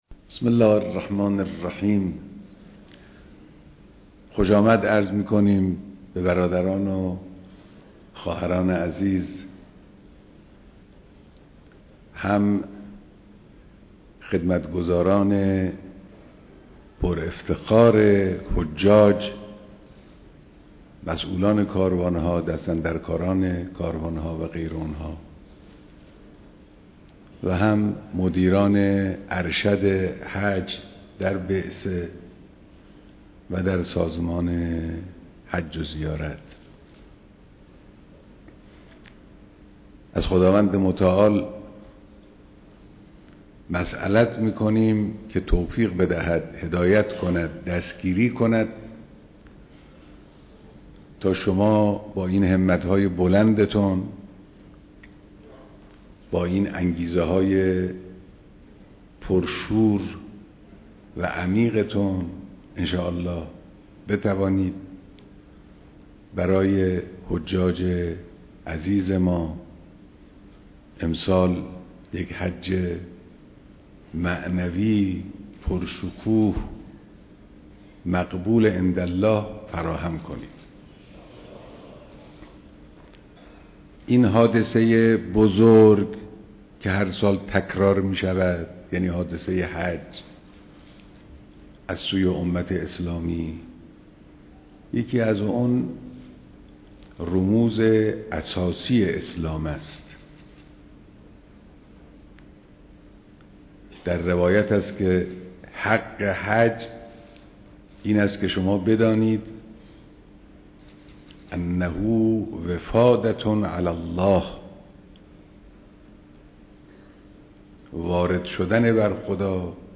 بيانات در ديدار كارگزاران حج‌